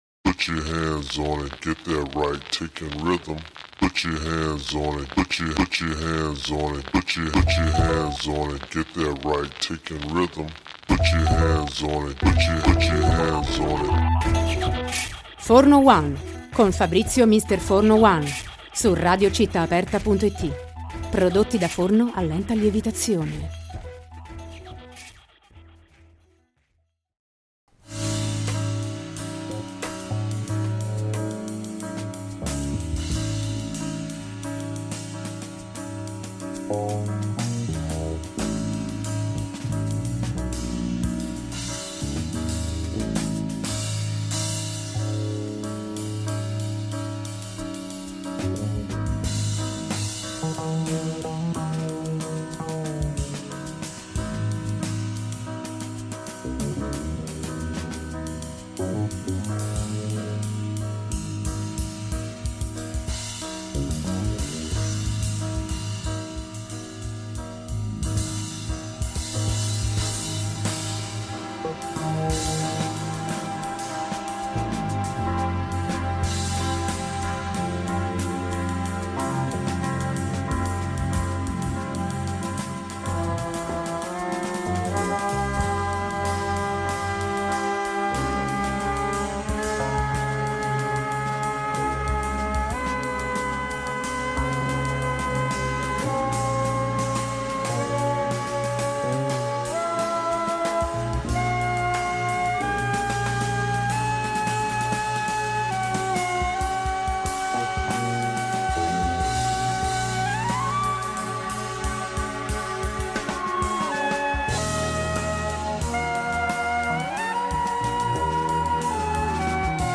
Scritti dal basso. Dieci bassisti tra jazz e letteratura – Intervista